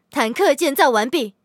LT-35建造完成提醒语音.OGG